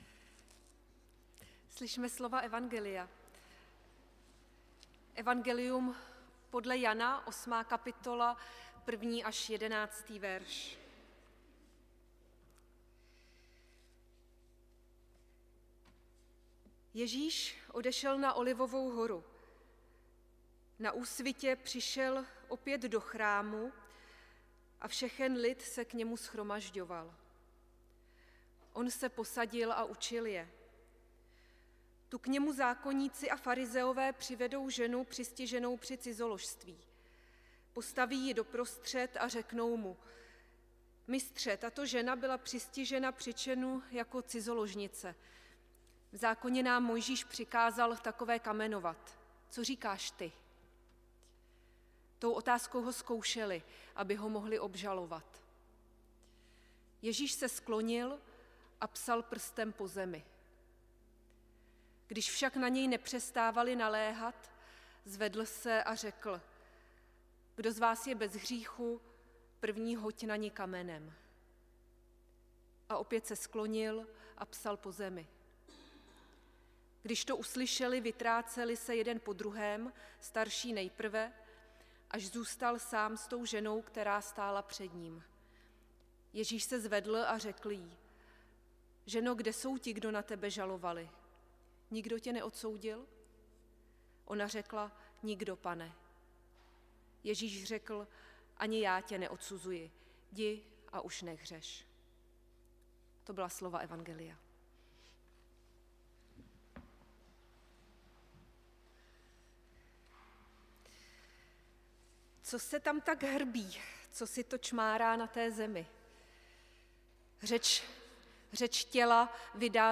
Bohoslužby s Večeří Páně 6. 4. 2025 • Farní sbor ČCE Plzeň - západní sbor
Pátá neděle postní 6. dubna 2025
Audio kázání: Janovo evangelium 8, 1 – 11